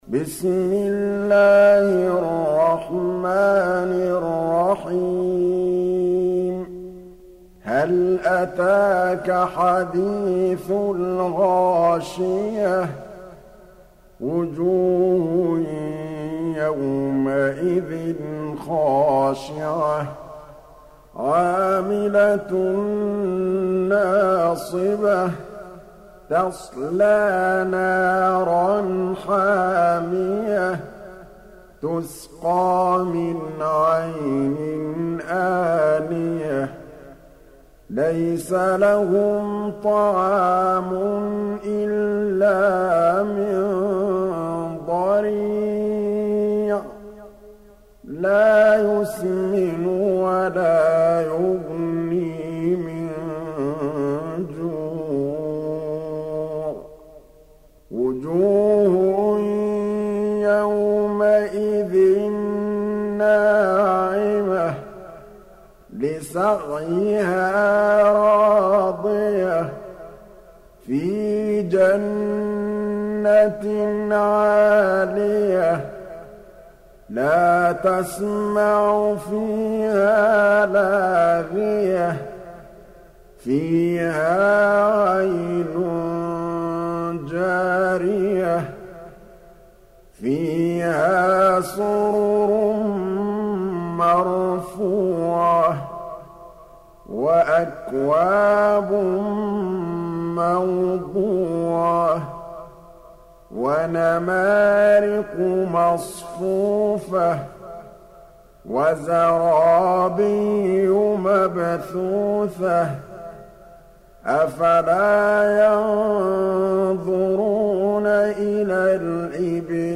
88. Surah Al-Gh�shiyah سورة الغاشية Audio Quran Tarteel Recitation
Surah Sequence تتابع السورة Download Surah حمّل السورة Reciting Murattalah Audio for 88.